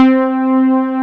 MOOG C5.wav